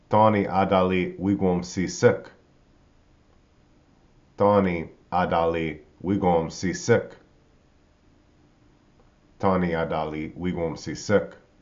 tô-ni   a-da-li  wig-wôm-si-sek